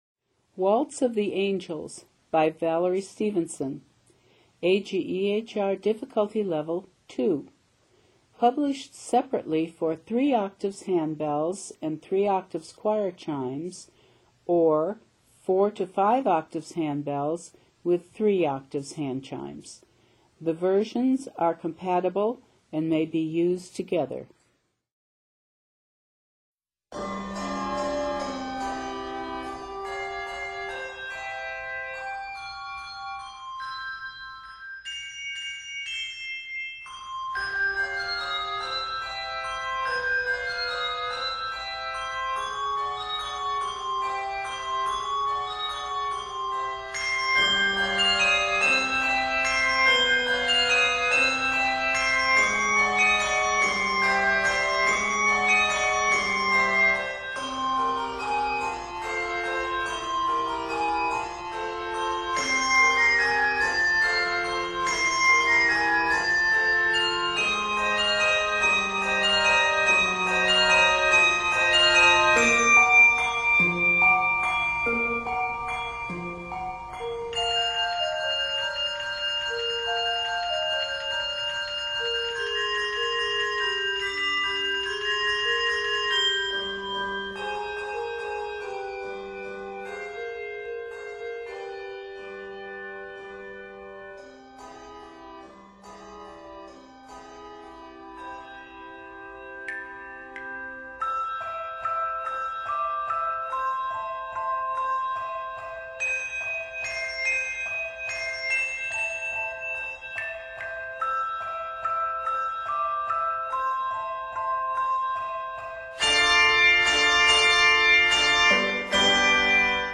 N/A Octaves: 4-5 Level